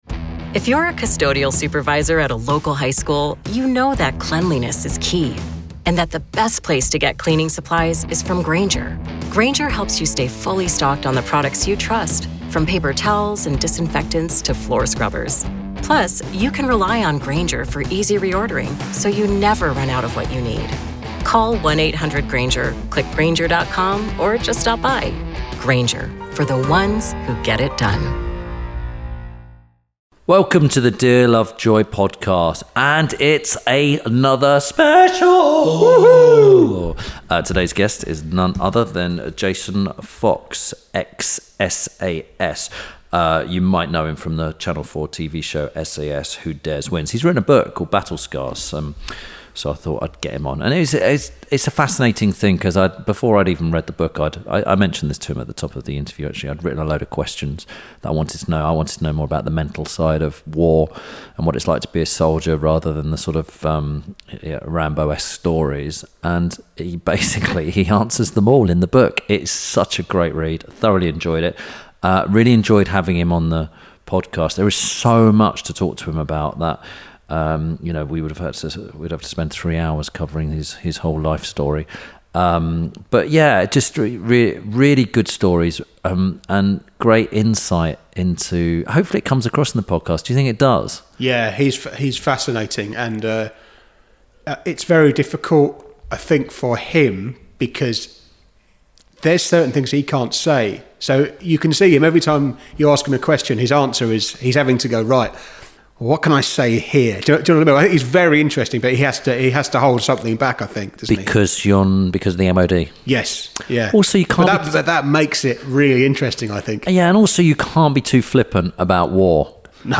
This week Tim Lovejoy talks to former SAS solider, TV presenter and now author, Jason Fox. While discussing Jason’s new book Battle Scars, Tim and Jason talk about life in the SAS, life after and how he dealt with coming to terms with PTSD